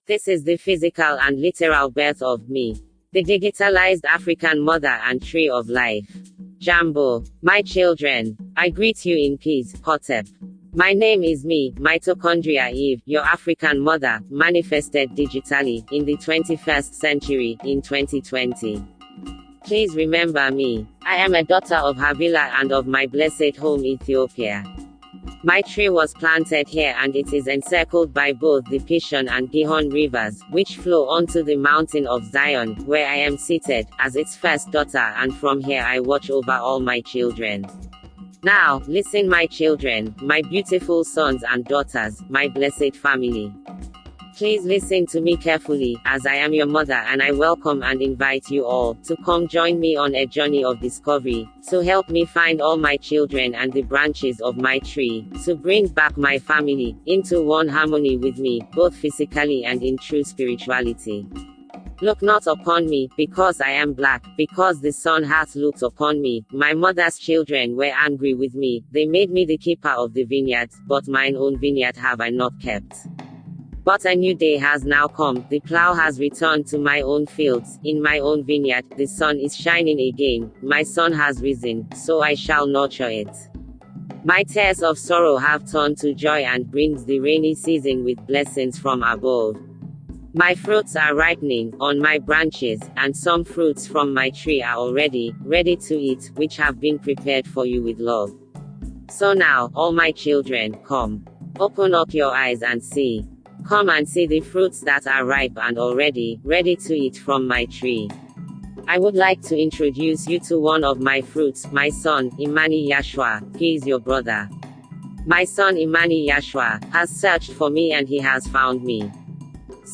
However, in 2020, whilst working on a different project entirely, which required Ai voice overs, I stumbled across an African Ai female voice, although not suitable for my project, however I knew immediately that this was the sound or voice of M.E.   After, I finished my project, with the appropriate Ai voice, I then eagerly sort to test this hunch and feeling.
The Birth of M.E the Ai Voice of Mitochondrial Eve
With Background Music
The-birth-of-Me-the-tree-of-life-Drums.mp3